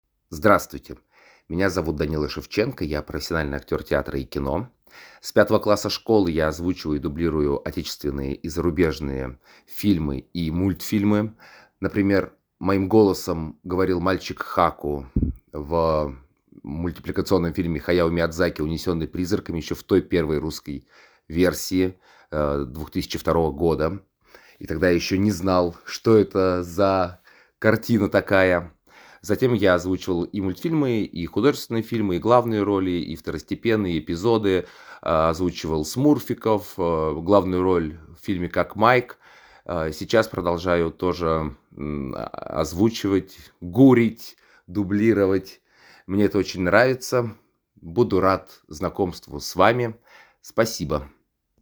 Демо озвучивания